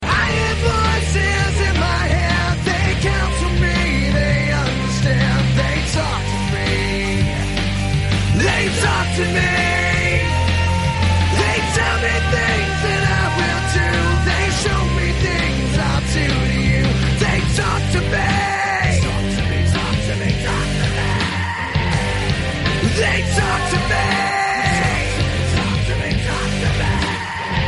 Synthetik